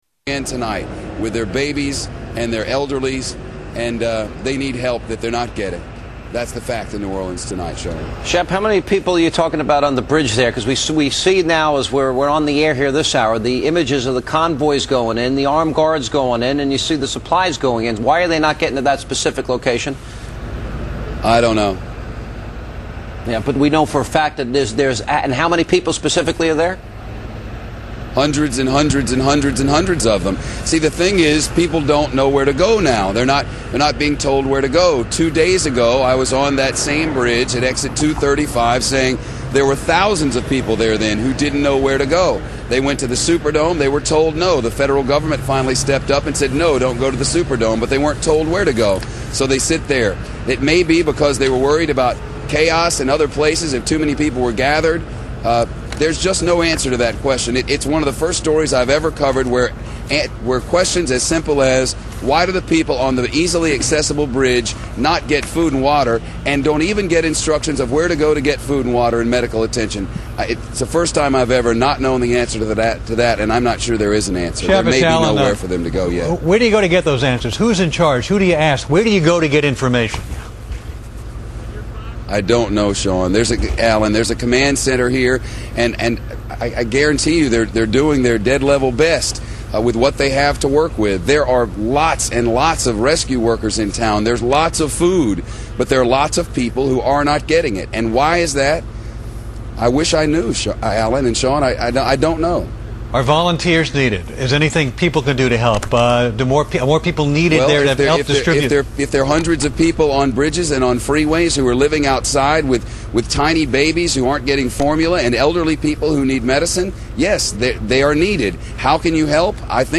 • Shepard Smith and Geraldo Rivera (in New Orleans) on Fox News Channel, trying to convince Sean Hannity and Alan Colmes that the situation at the convention center was much worse than it may have seemed from their dry, warm New York studio.